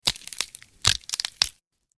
Breaking Bones Sound Effect
Realistic breaking bones sound effect for videos, games, and media projects. Perfect for horror, action, or intense scenes needing a sharp, impactful sound.
Breaking-bones-sound-effect.mp3